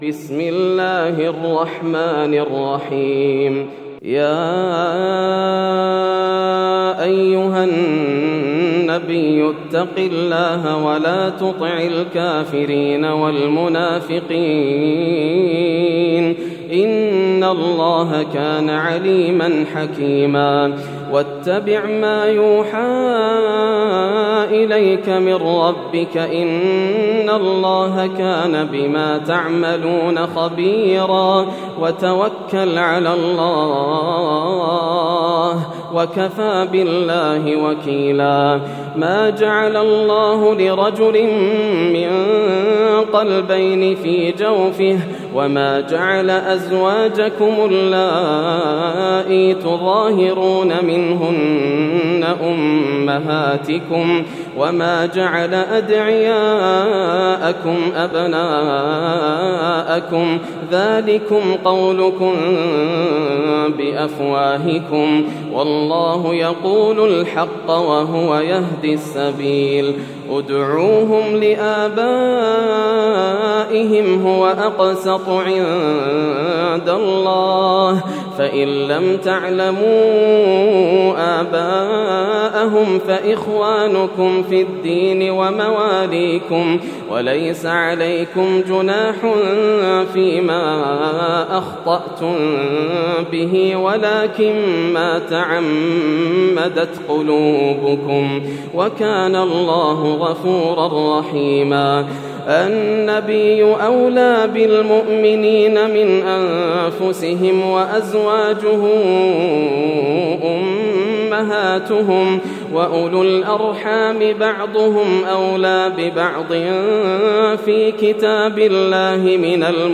سورة الأحزاب > السور المكتملة > رمضان 1431هـ > التراويح - تلاوات ياسر الدوسري